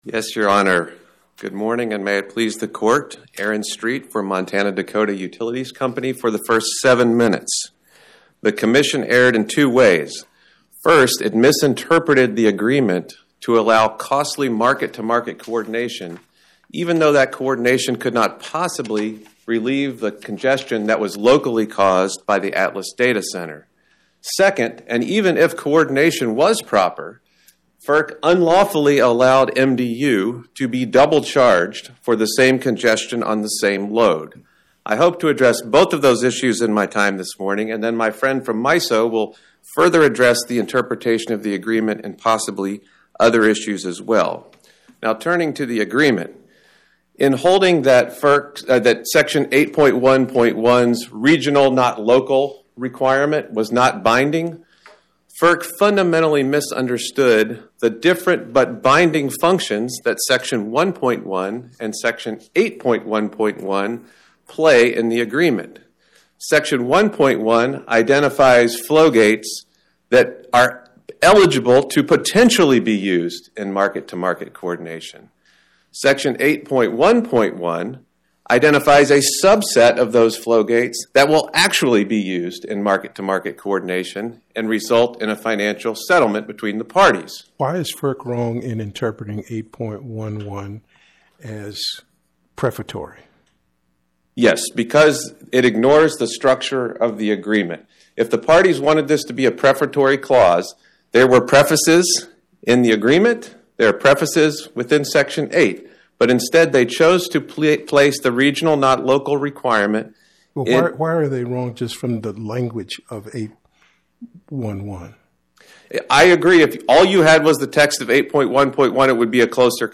My Sentiment & Notes 25-1007: Montana-Dakota Utilities Co. vs FERC Podcast: Oral Arguments from the Eighth Circuit U.S. Court of Appeals Published On: Thu Feb 12 2026 Description: Oral argument argued before the Eighth Circuit U.S. Court of Appeals on or about 02/12/2026